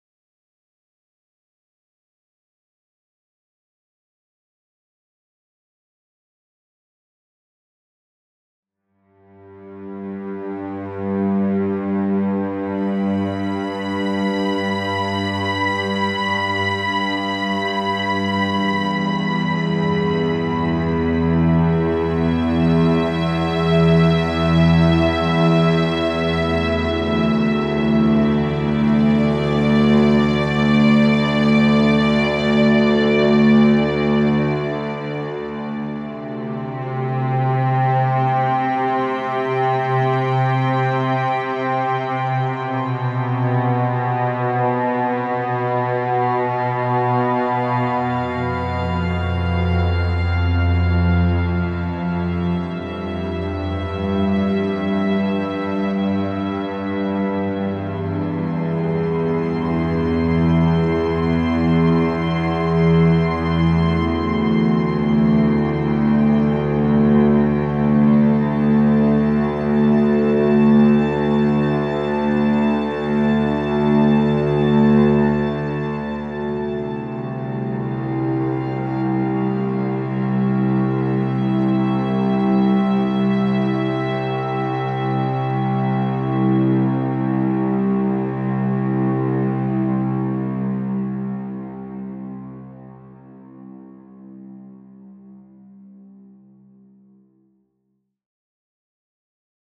Soundtrack-for-the-sad-part.mp3